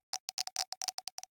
Minecraft Version Minecraft Version latest Latest Release | Latest Snapshot latest / assets / minecraft / sounds / ambient / nether / basalt_deltas / click3.ogg Compare With Compare With Latest Release | Latest Snapshot
click3.ogg